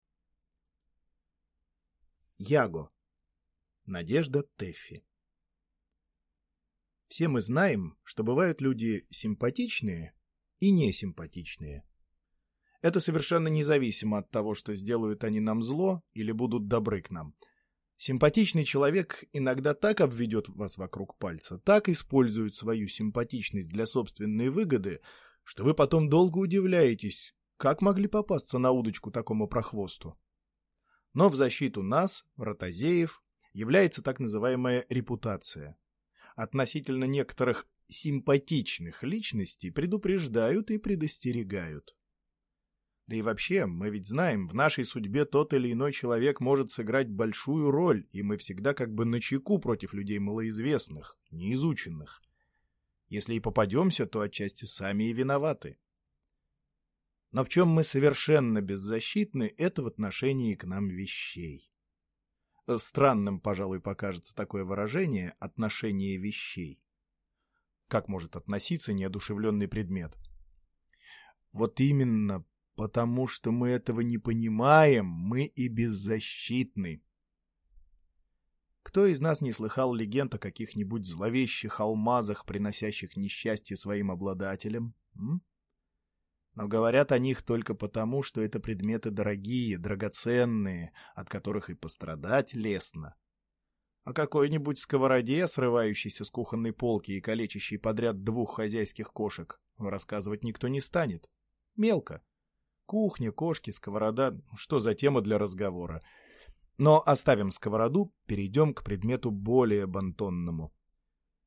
Аудиокнига Яго | Библиотека аудиокниг